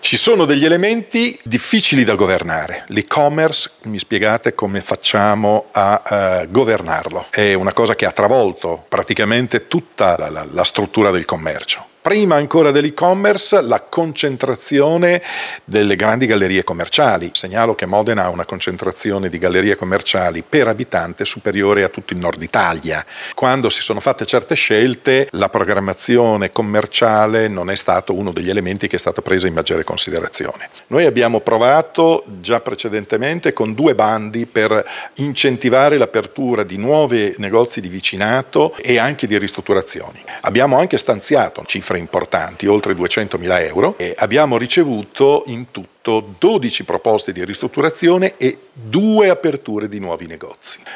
Sentiamo l’assessore alla promozione economica Paolo Zanca: